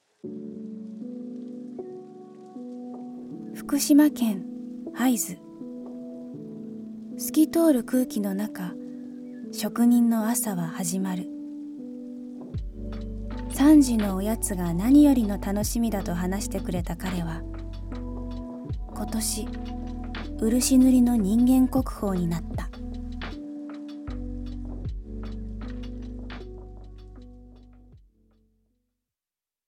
ナレーション
ボイスサンプル